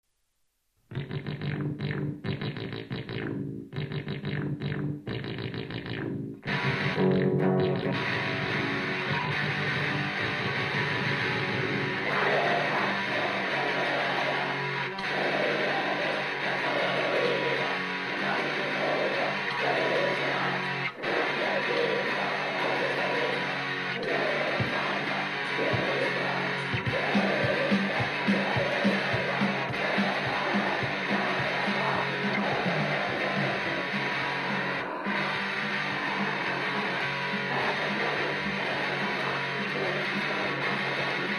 Запись крайне трушная...
keyboard, percussions, vocal, backvocal.
guitar, vocal, backvocal.